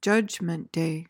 PRONUNCIATION:
(JUHJ-muhnt day)